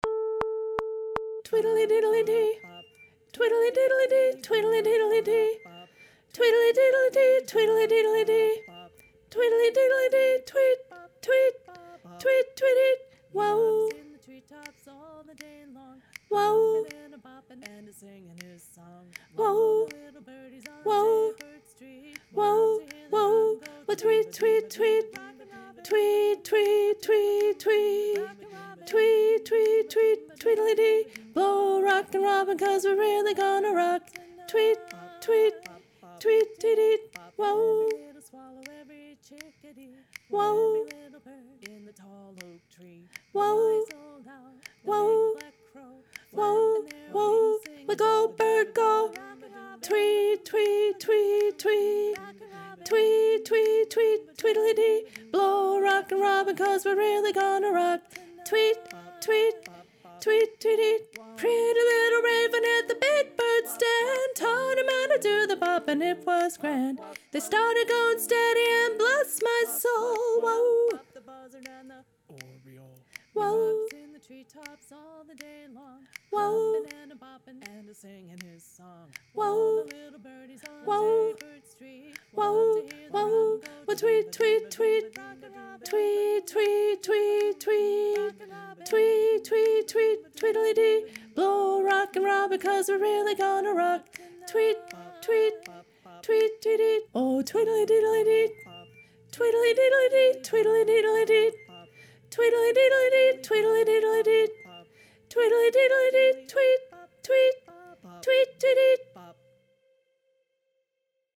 Soprano 1 (Tenor)